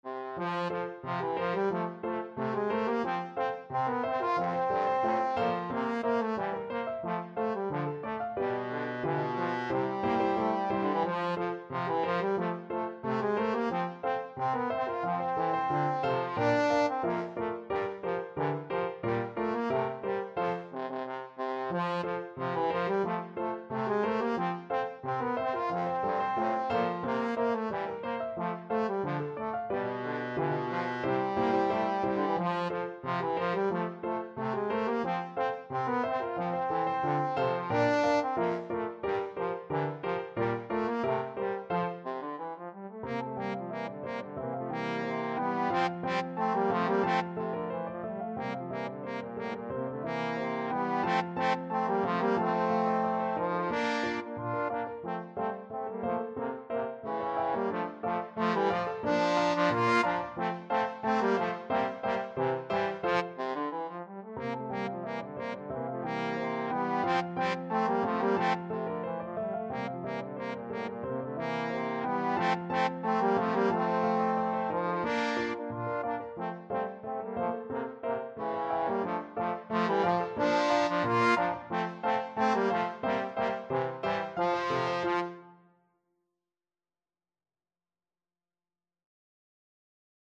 Trombone Duet  (View more Intermediate Trombone Duet Music)
Traditional (View more Traditional Trombone Duet Music)